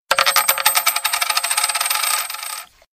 Монетка На Столе